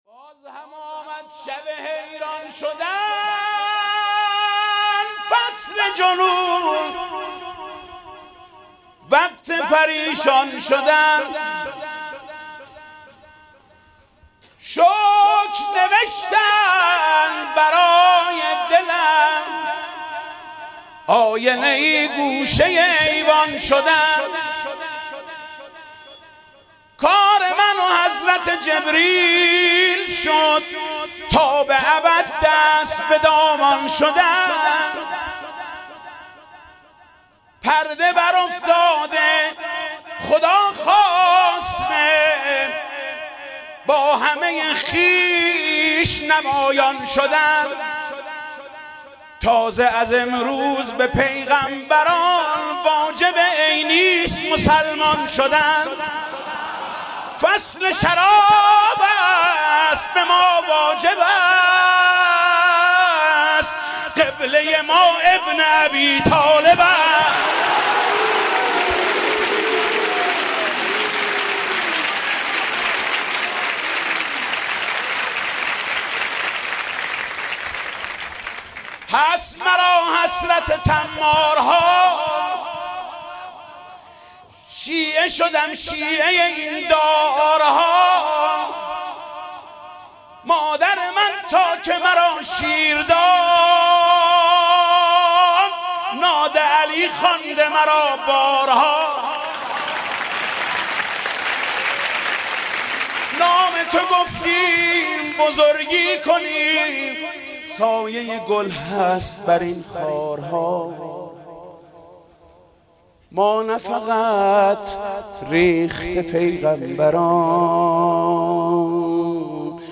مدح: باز هم آمد شب حیران شدن